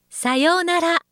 ホームページ作成で利用できる、さまざまな文章や単語を、プロナレーターがナレーション録音しています。
047-sayounara.mp3